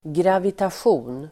Uttal: [gravitasj'o:n]